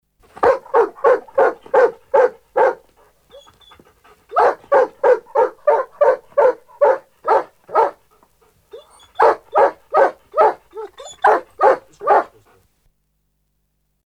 دانلود صدای مرغ عشق جوان و پر انرژی از ساعد نیوز با لینک مستقیم و کیفیت بالا
جلوه های صوتی